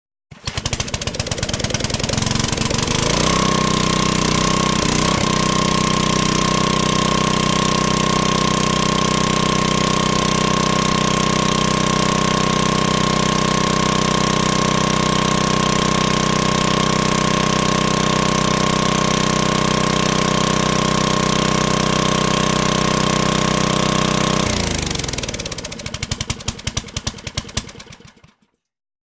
Electric Generators
Electric generator starts and shuts off. Electric Generator Motor, Generator Engine, Generator